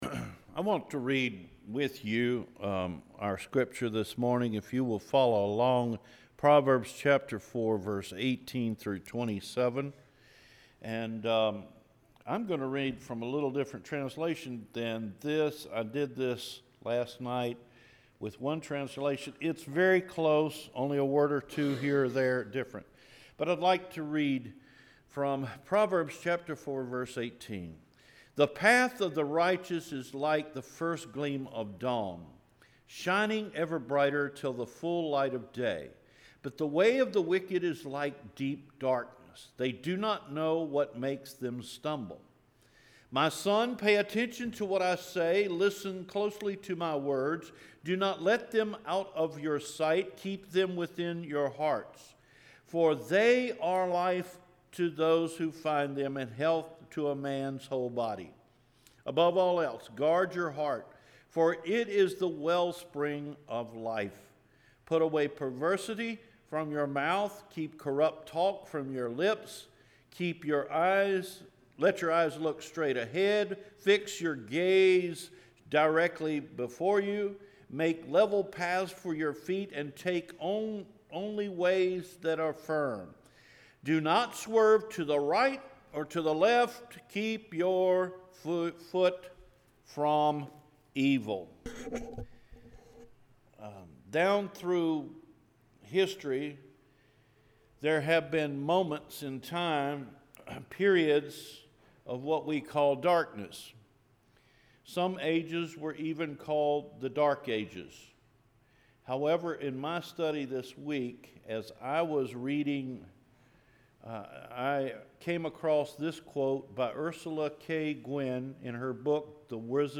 Living in the Dark Ages – June 11 2017 Sermon